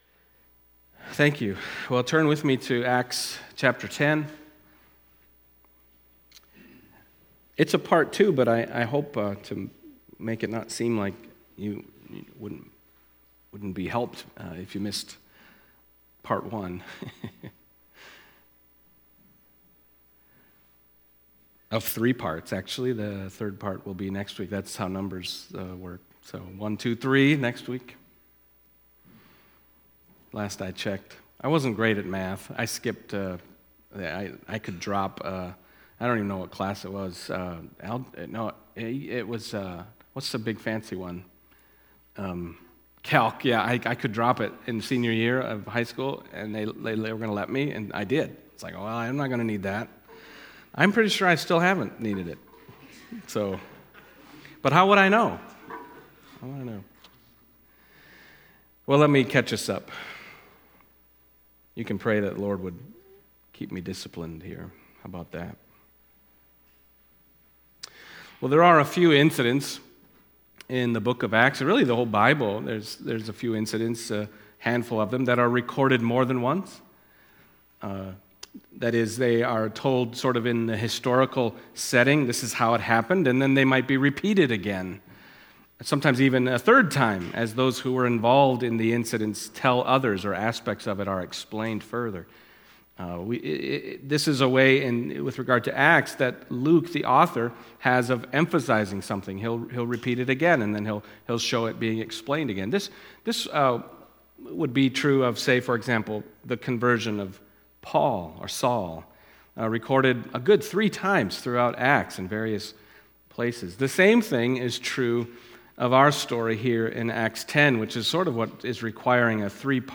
Passage: Acts 10:23-48 Service Type: Sunday Morning Acts 10:23b-48 « And Also to the Gentiles